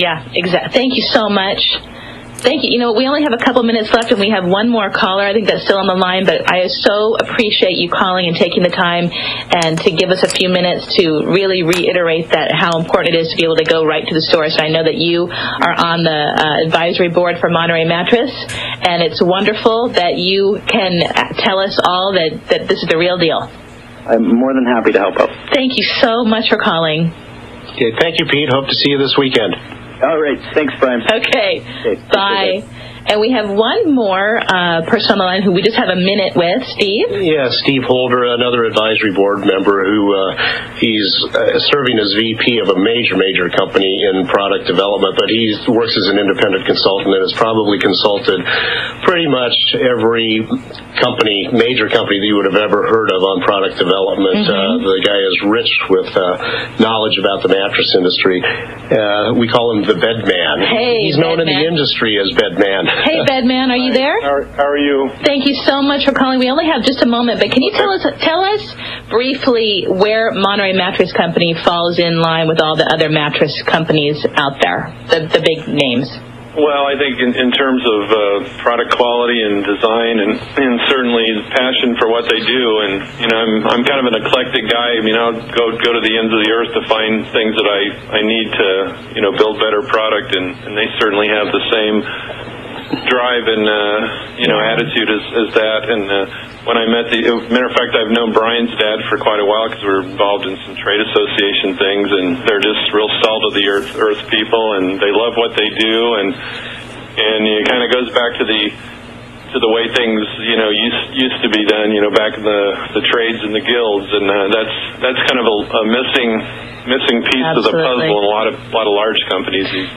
Radio Inverview with Monterey Mattress 7-30-9